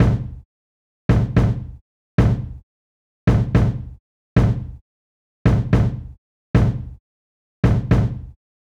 30 Kick.wav